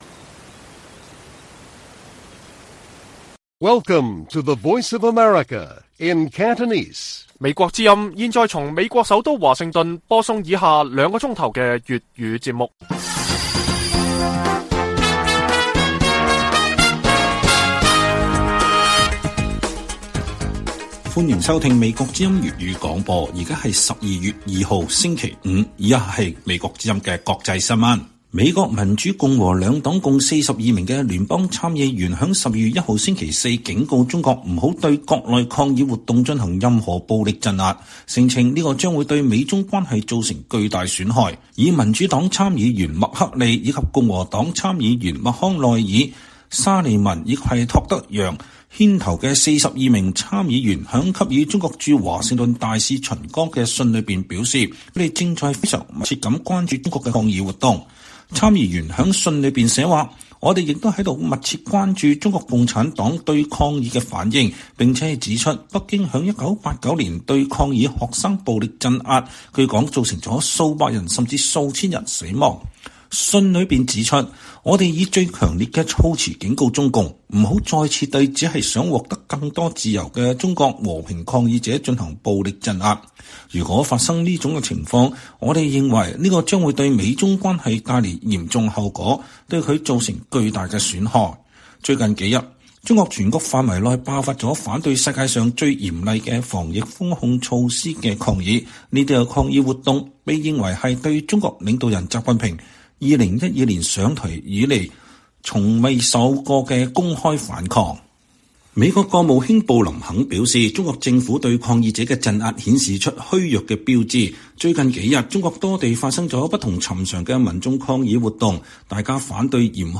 粵語新聞 晚上9-10點 : “人民領袖”跌落神壇，習近平如何化解清零危機？